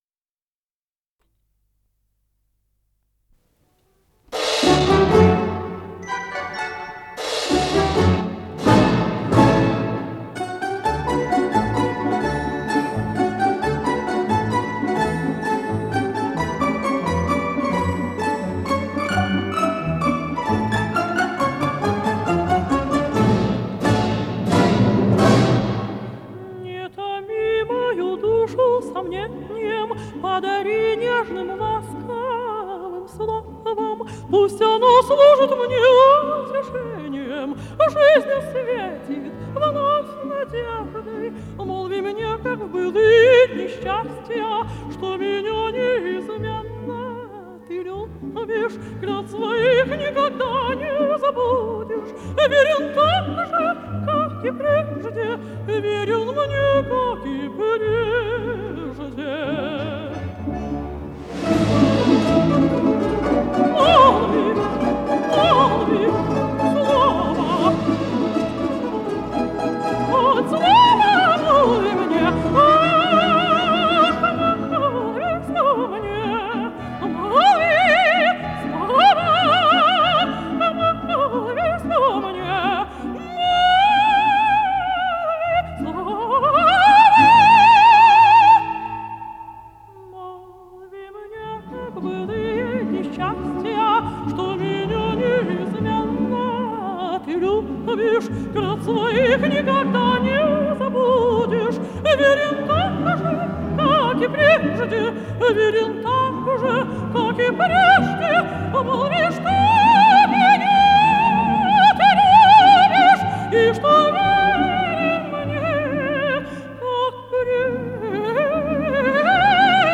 с профессиональной магнитной ленты
ПодзаголовокВальс
АккомпаниментОркестр русских народных инструментов Всесоюзного радио и Центрального телевидения
Художественный руководитель и дирижёр - Владимир Федосеев
ВариантДубль моно